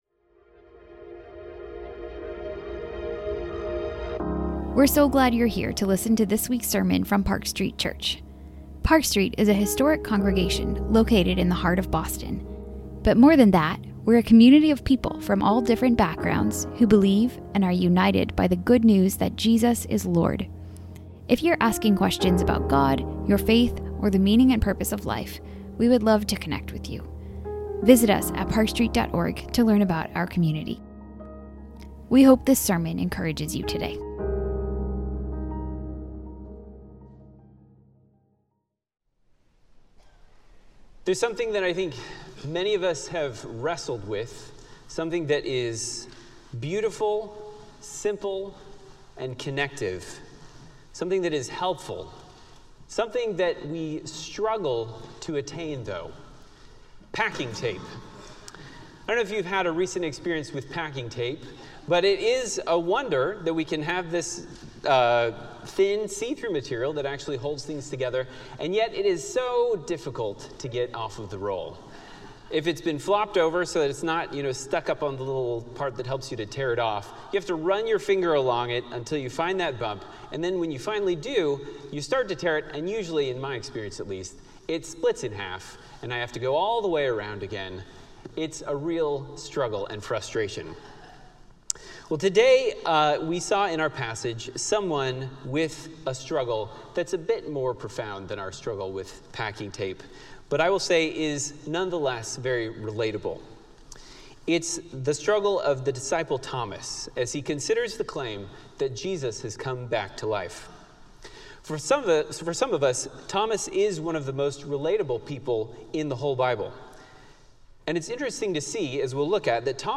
A message from the series "Psalms of Jesus."